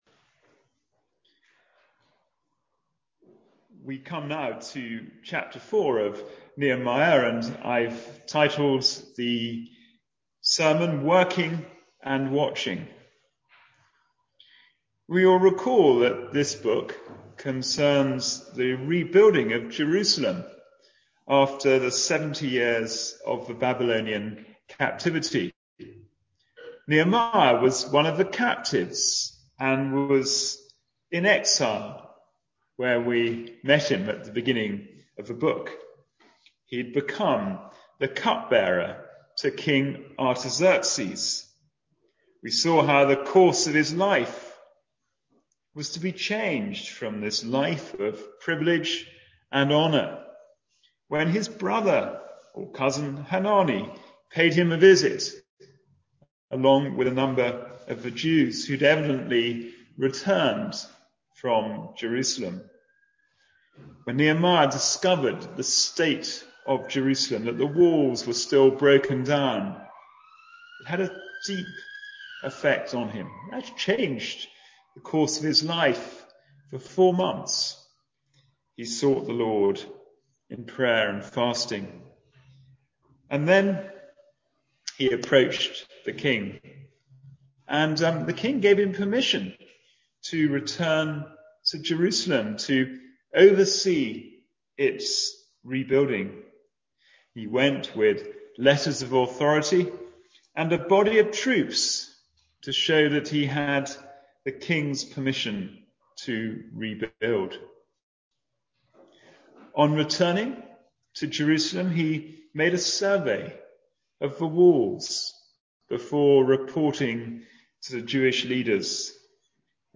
Passage: Nehemiah 4 Service Type: Sunday Morning Service